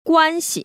[guān‧xi]
꽌시